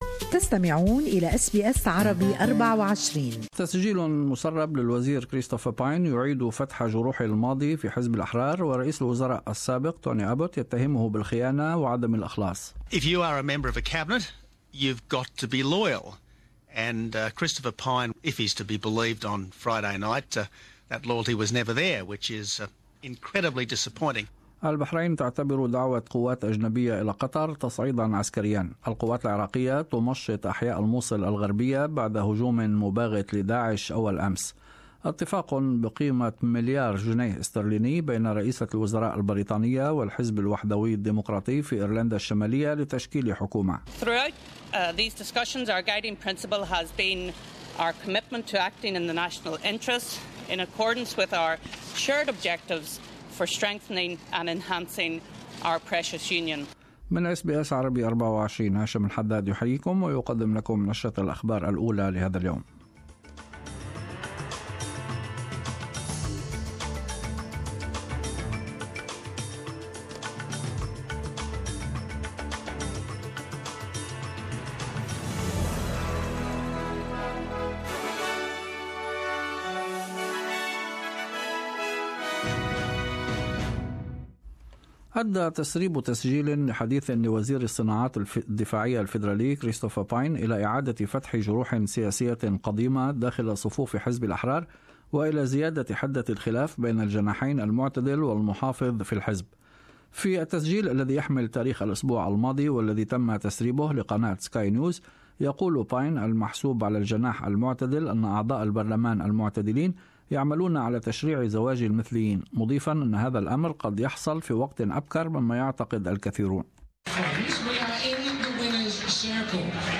Morning news bulletin.